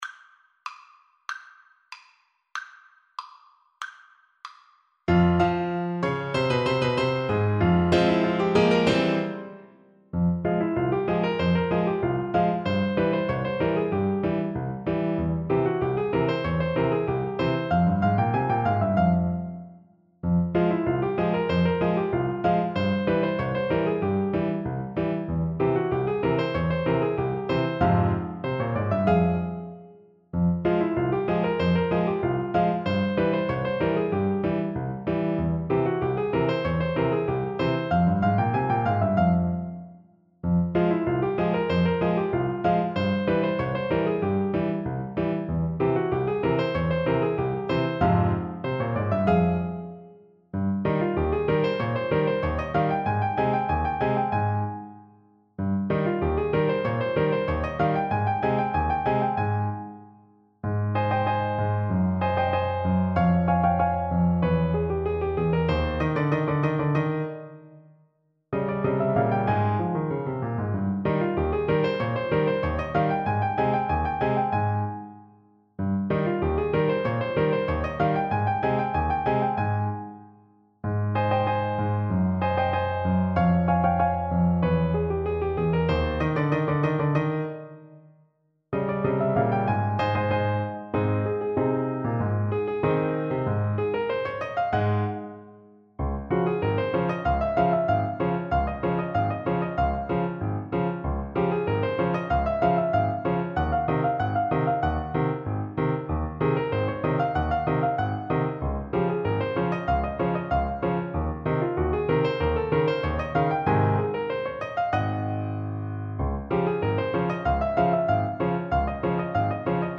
Molto allegro = 132
2/2 (View more 2/2 Music)
Ab3-F5
Classical (View more Classical Trombone Music)